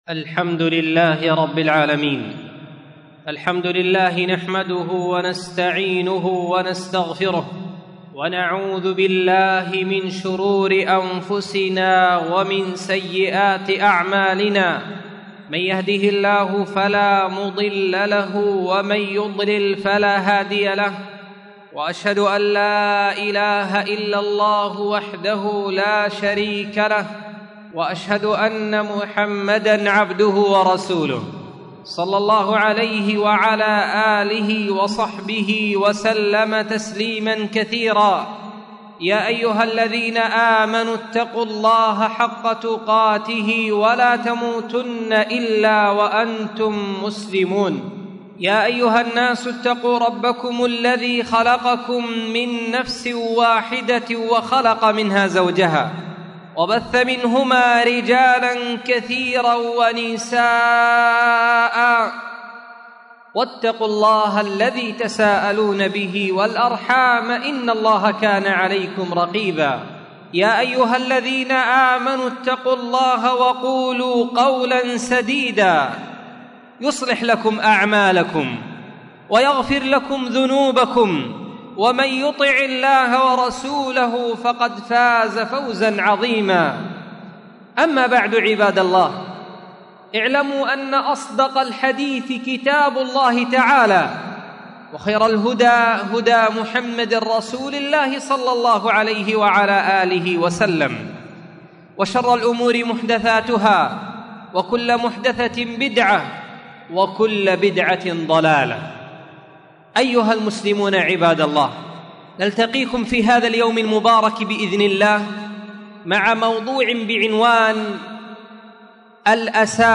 مسجد درة عدن مدينة درة عدن( محافظة عدن حرسها الله... 1447:23:7 الذكر والشكر قاعدتان لبناء الدين